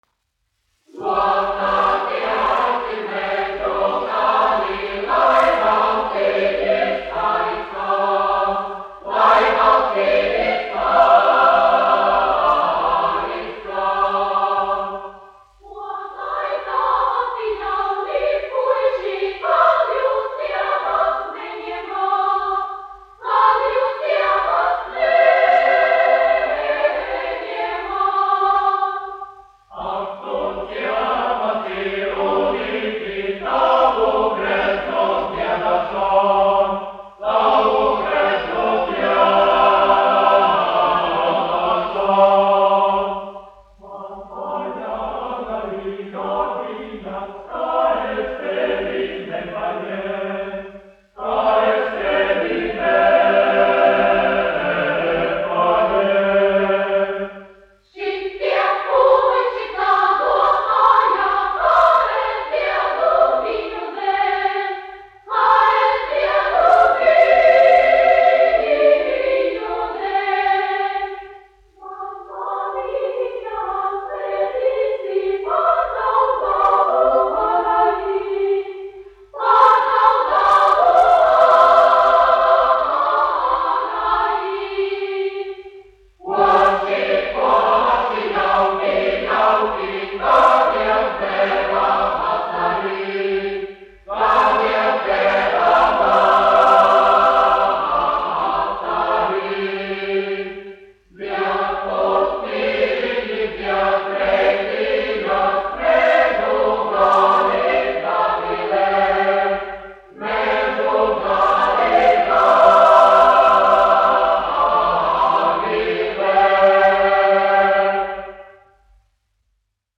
Lokatiesi, mežu gali : latviešu tautas dziesma
Emilis Melngailis, 1874-1954, aranžētājs
Daugavpils Kultūras nama Nr. 1 jauktais koris, izpildītājs
1 skpl. : analogs, 78 apgr/min, mono ; 25 cm
Kori (jauktie)
Skaņuplate